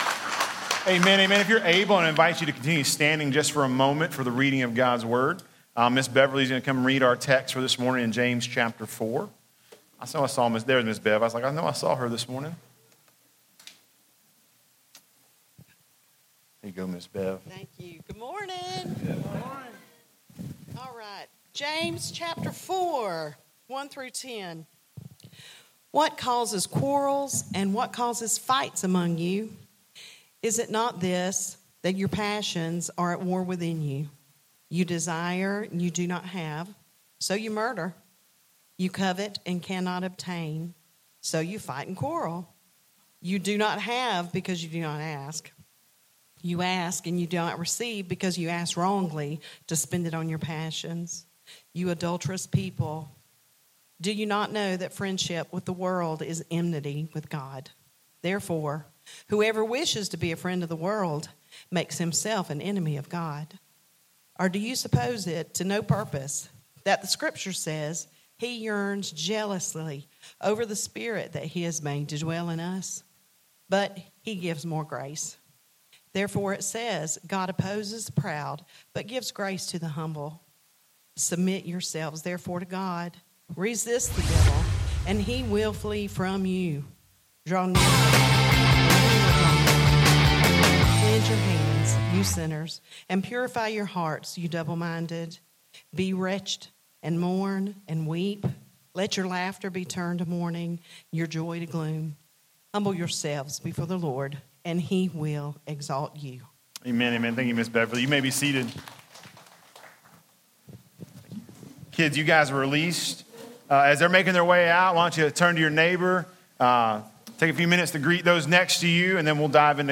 Sermons | Replicate Church